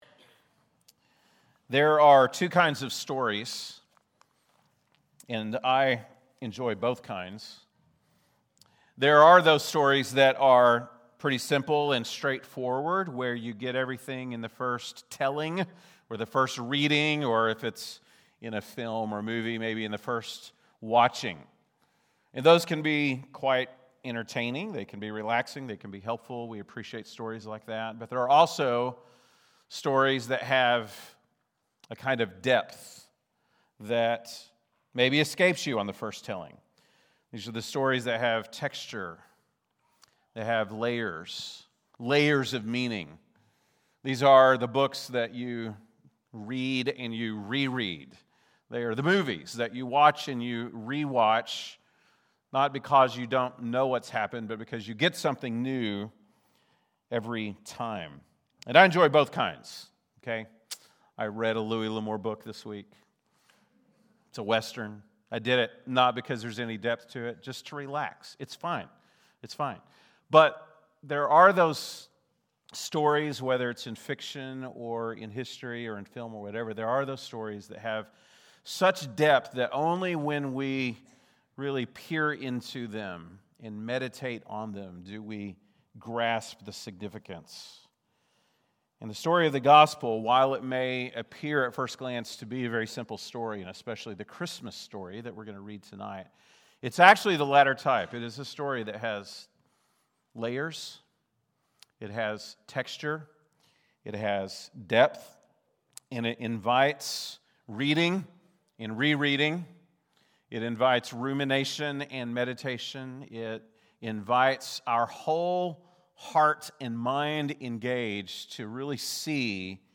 December 24, 2025 (Çhristmas Eve)